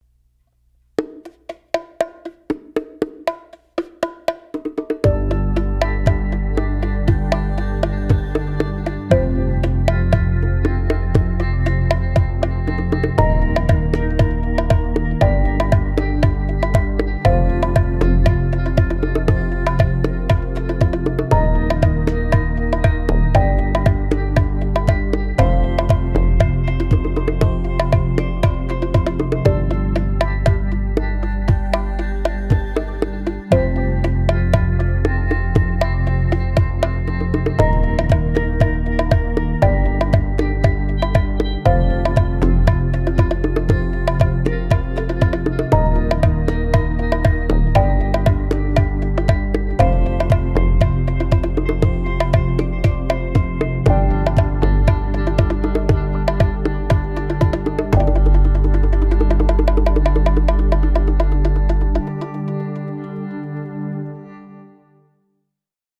MEINL Percussion Headliner Series Wood Bongo 6 3/4" MACHO & 8" HEMBRA - Natural (HB100NT)